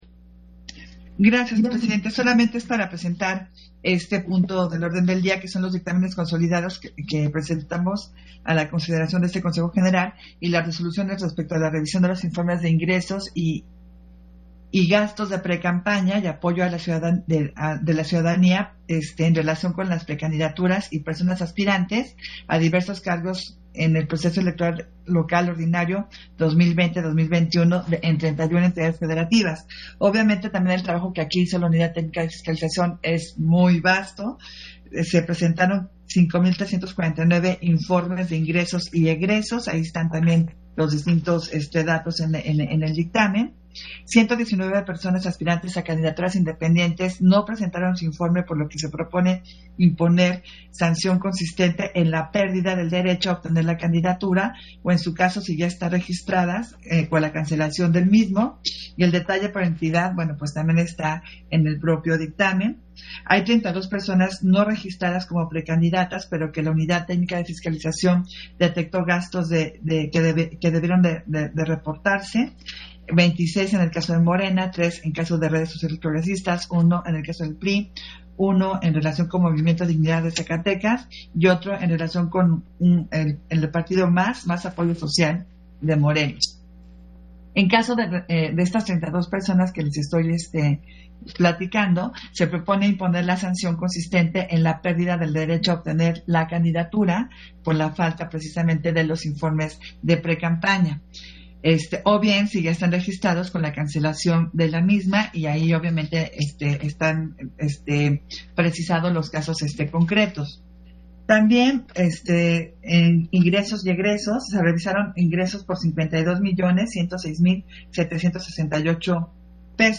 Intervención de Adriana Favela, en el punto de la Sesión Ordinaria, respecto de la revisión de informes de ingresos, gastos de campaña y apoyo de la ciudadanía presentados por precandidaturas y aspirantes a diversos cargo de elección popular